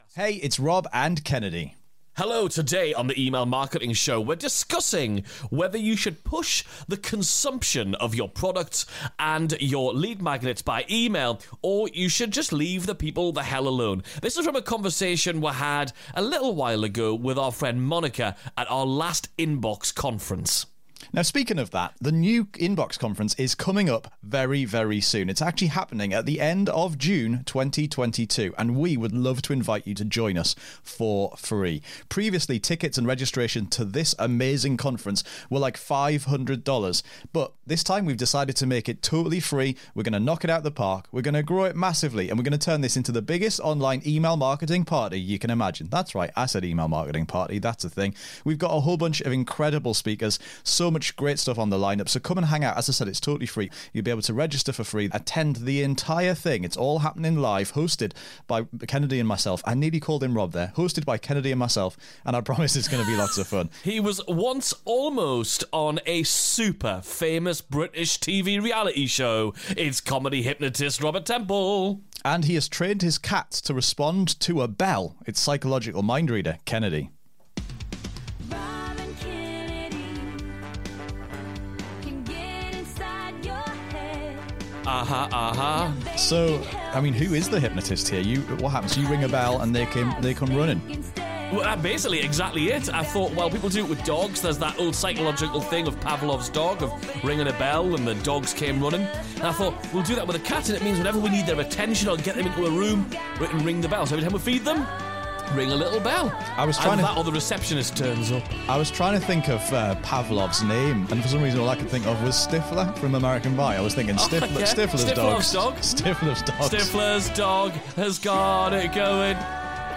Today we're covering content consumption by sharing an exclusive panel discussion